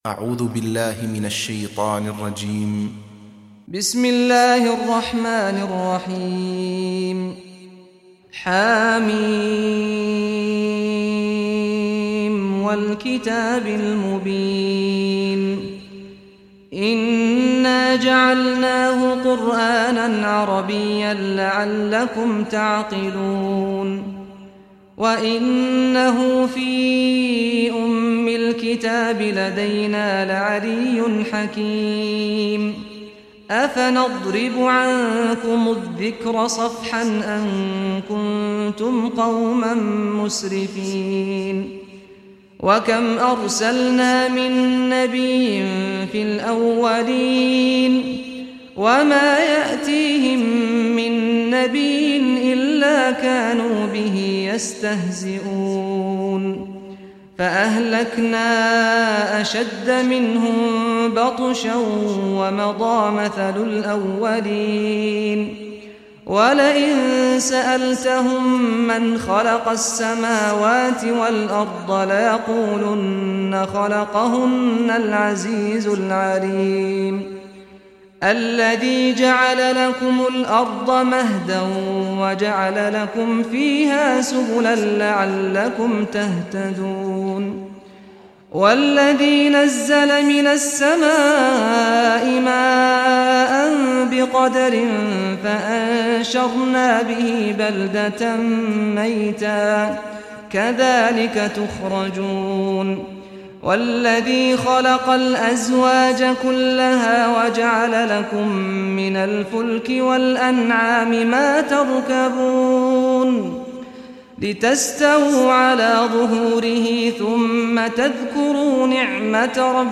Surah Az-Zukhruf Recitation by Sheikh Saad Ghamdi
Surah Az-Zukhruf, listen or play online mp3 tilawat/ recitation in Arabic in the beautiful voice of Sheikh Saad al Ghamdi.